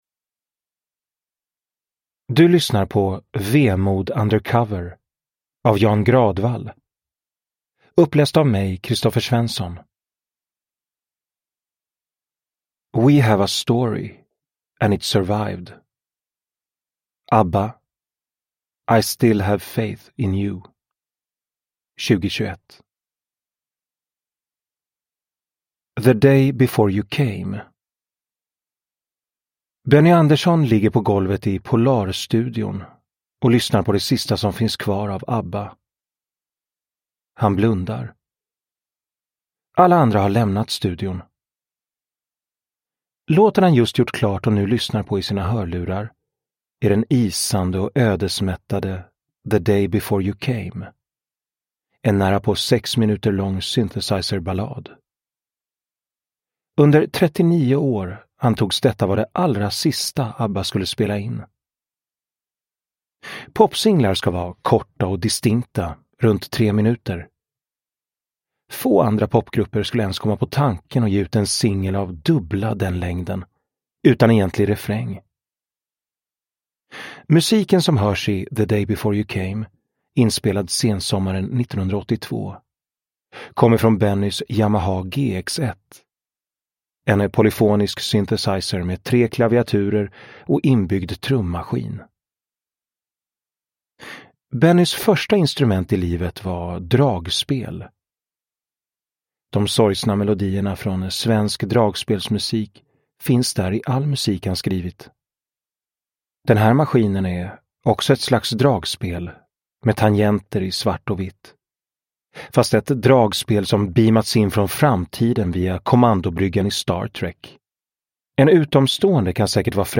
Nedladdningsbar Ljudbok
Ljudbok